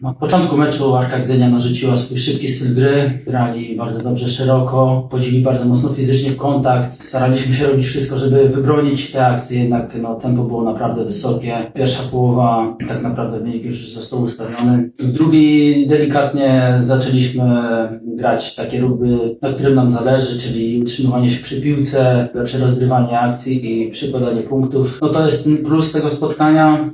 Komentował na pomeczowej konferencji prasowej